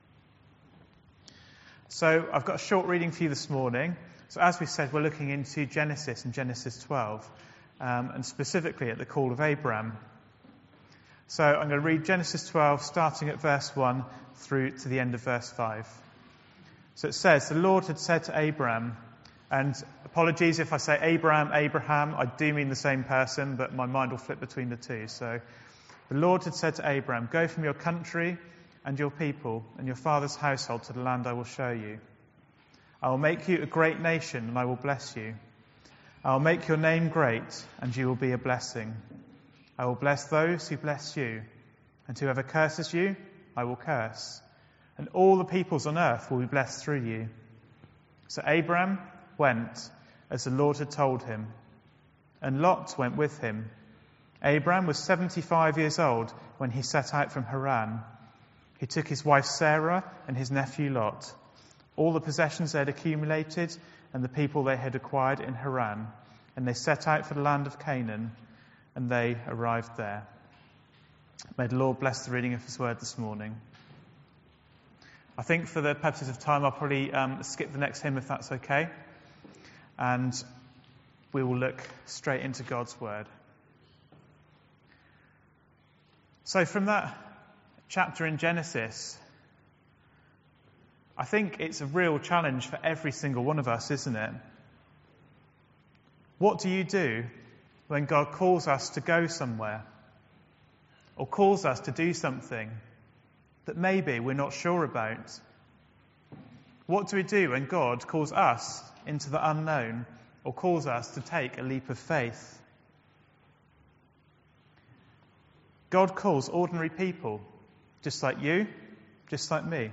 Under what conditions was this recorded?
This morning’s service considers the call of Abram from Genesis 12